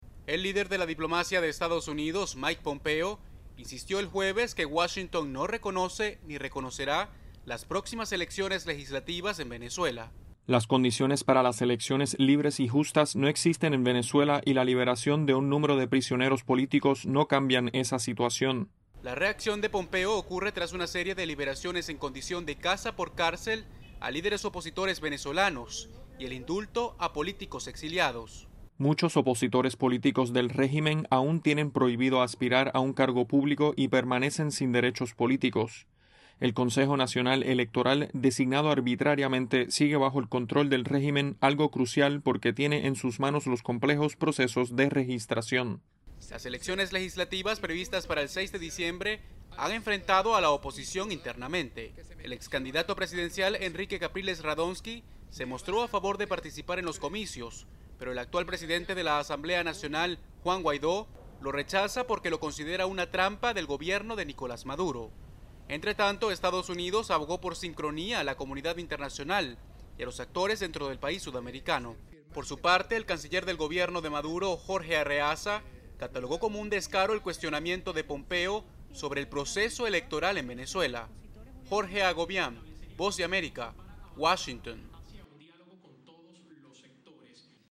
desde la Voz de América en Washington, D.C.